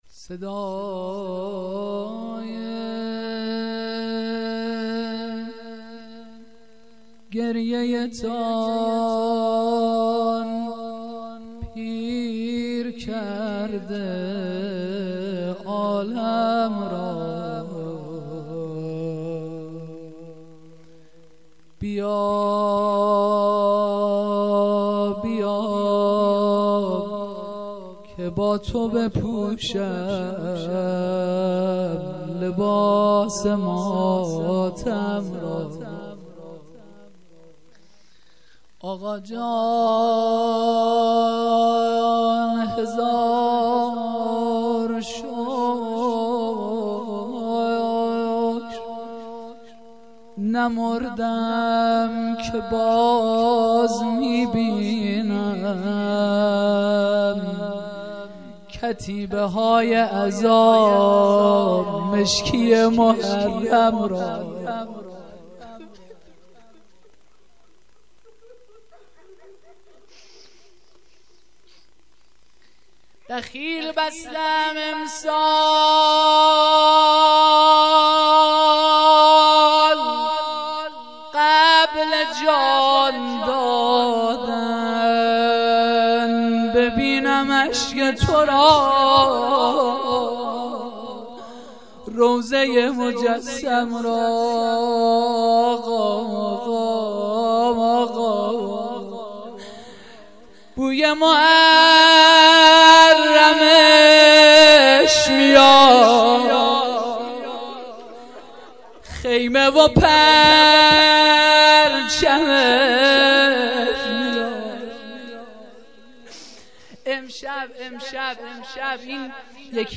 جلسه مذهبی زیارت آل یاسین باغشهر اسلامیه
روضه